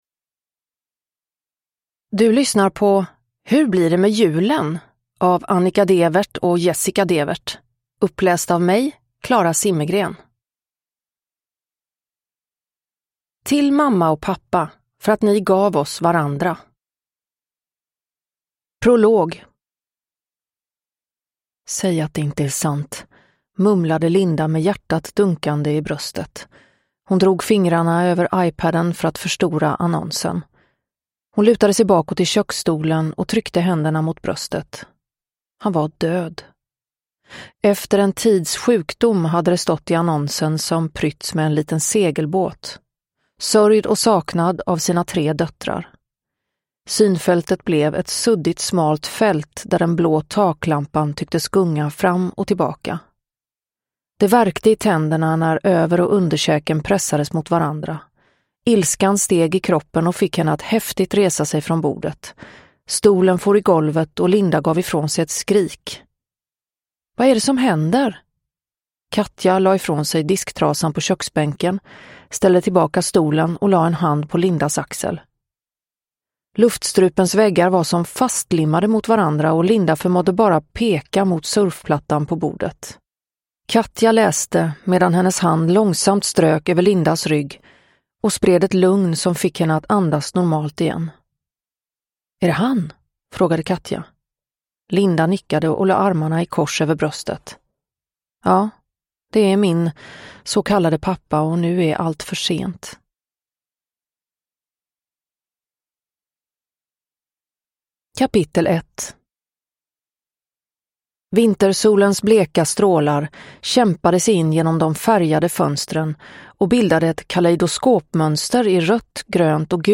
Hur blir det med julen? – Ljudbok – Laddas ner
Uppläsare: Klara Zimmergren